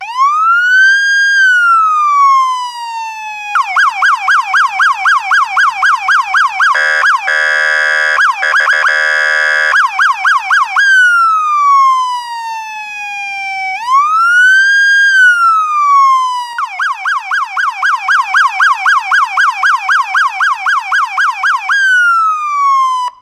Des sirènes jusqu'à 200 watts
La traditionnelle sirène que l'on peut écouter aux États-Unis est bien plus puissante que la sirène dites "deux tons" des véhicules français.
ÉCOUTEZ - Les différents signals sonores des voitures de police américaines :
alarmes-police-us-14964.mp3